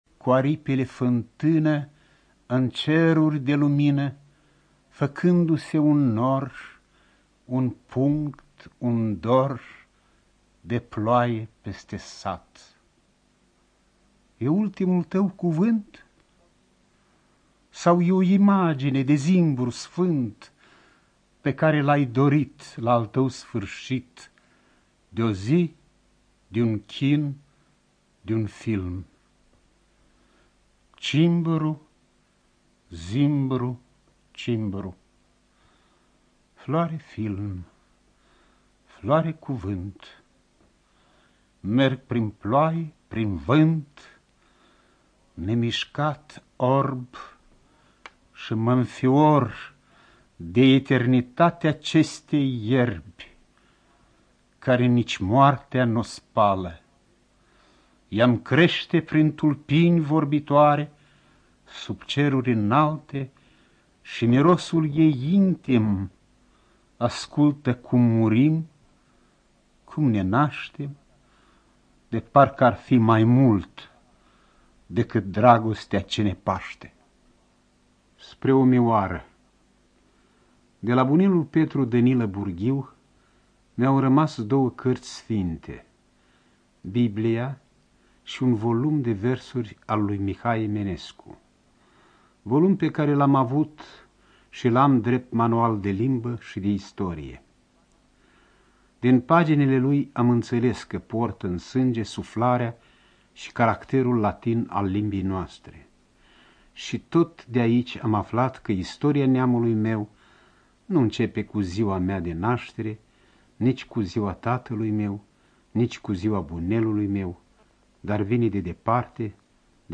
Студия звукозаписиНациональный Информационно-реабилитационный Центр Ассоциации Незрячих Молдовы